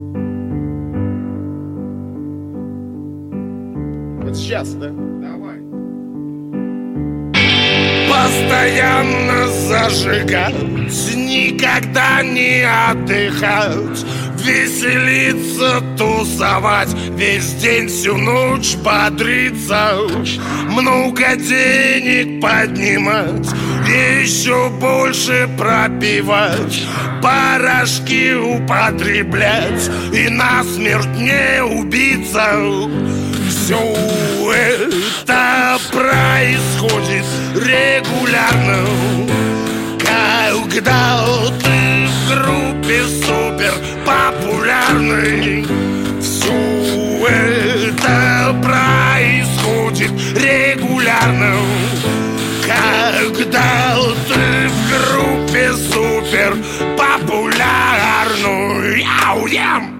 Рок
А теперь вышел и студийный альбом!